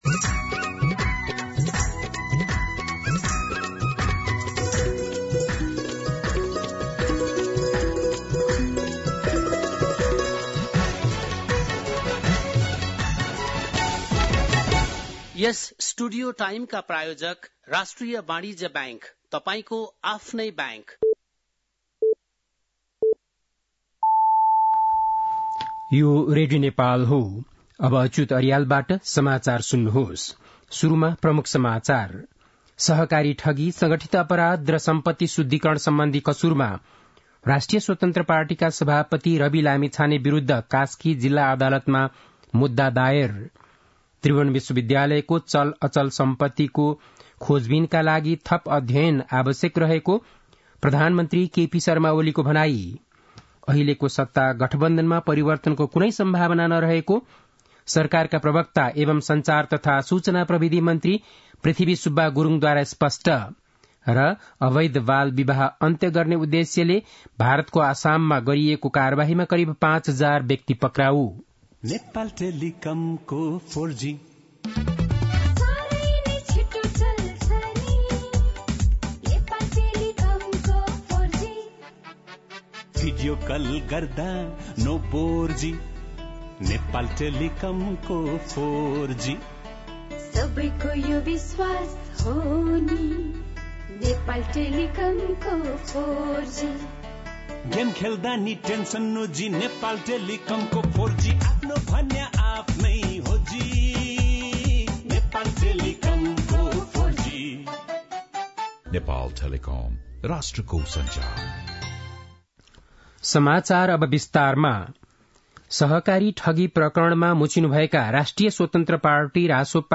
बेलुकी ७ बजेको नेपाली समाचार : ८ पुष , २०८१
7-PM-Nepali-News-9-7.mp3